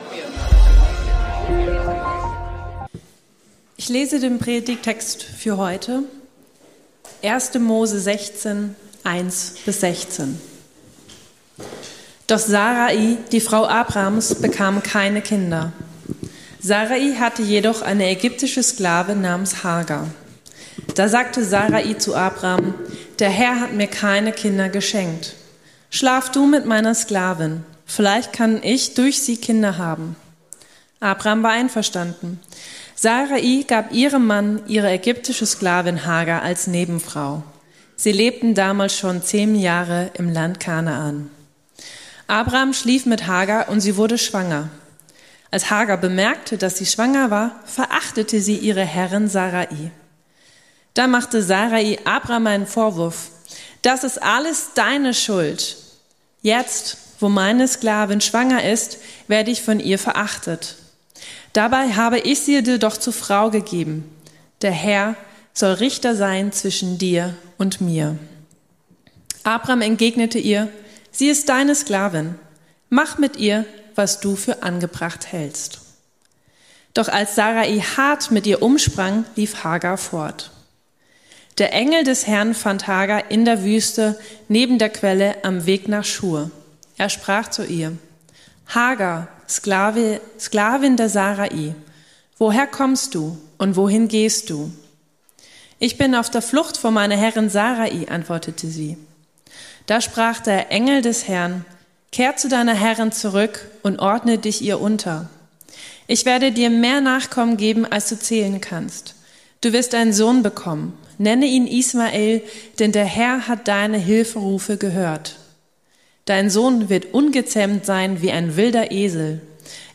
Du bist ein Gott, der mich sieht ~ Predigten der LUKAS GEMEINDE Podcast